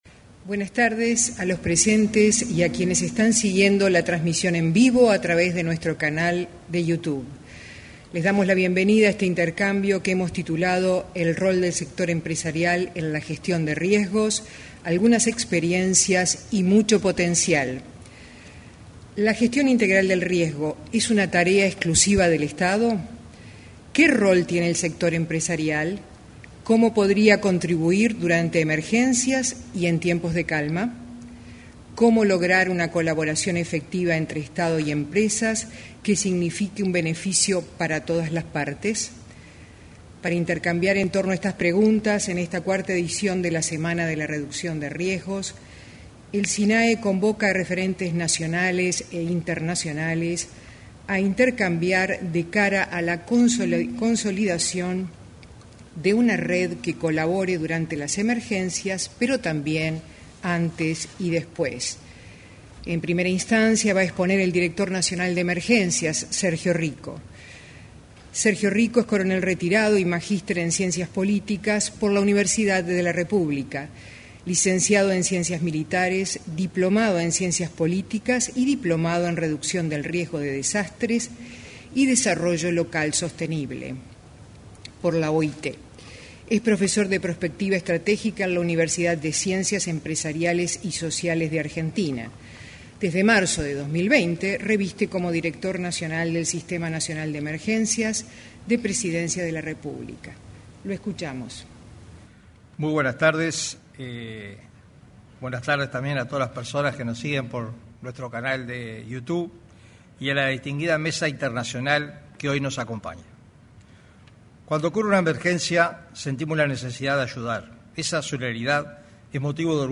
Cierre de la 4.ª edición de la Semana de la Reducción de Riesgos promovida por el Sistema Nacional de Emergencias (Sinae)